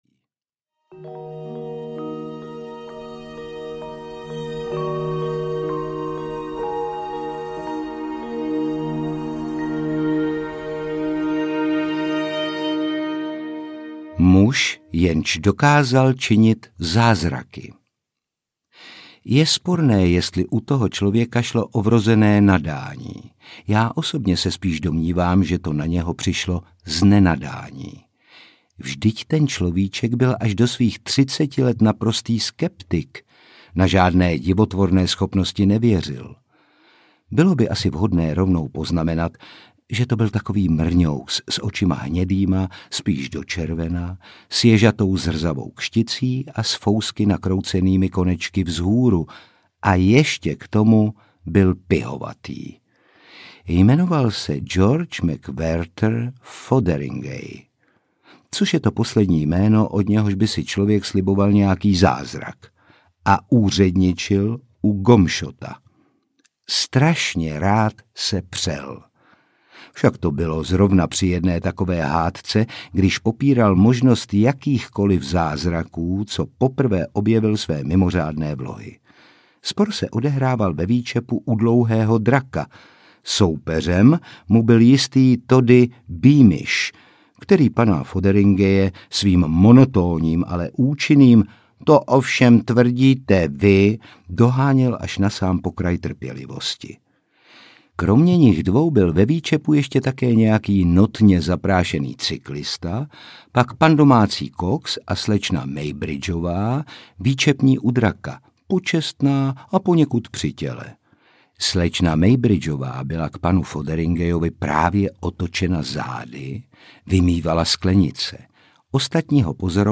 Povídky s X audiokniha
Ukázka z knihy
• InterpretOtakar Brousek ml.